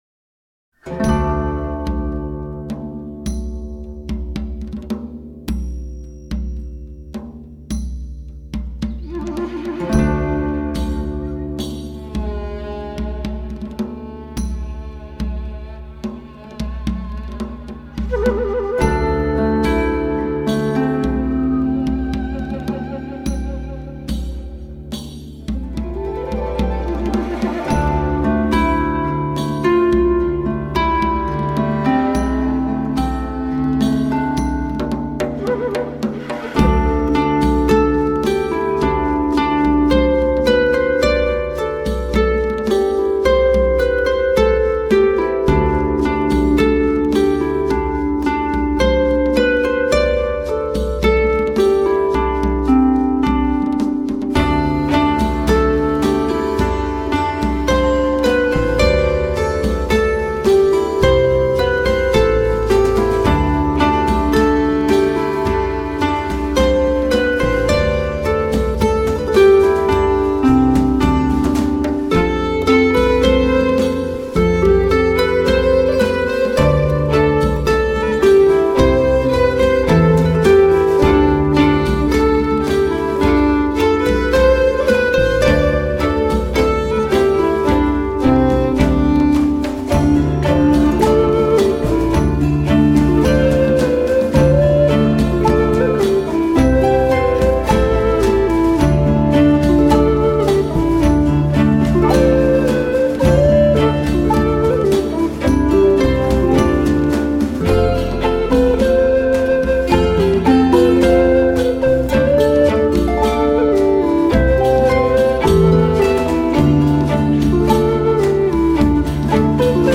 Genre：Newage